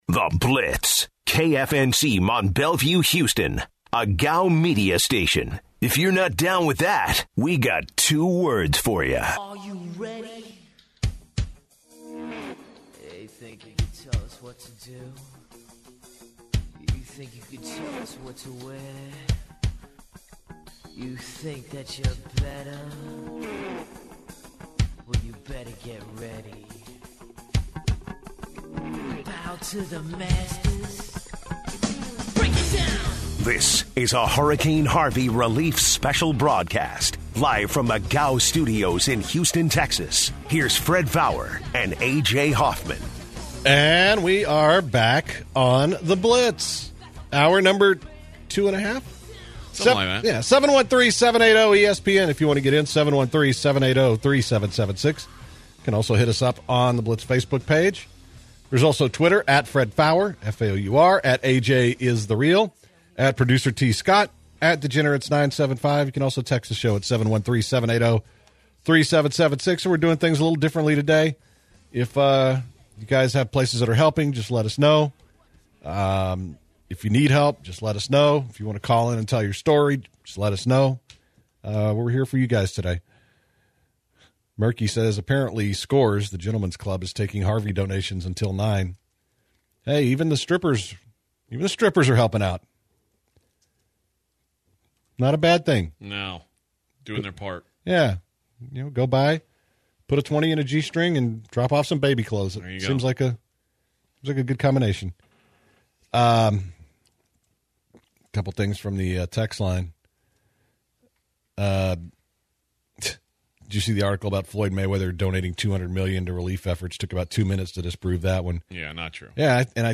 In Hour 2 the guys take some calls about where to help your fellow Houstonian and what you can do to help.